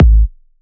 VFH3 130BPM Pressure 2 Kick.wav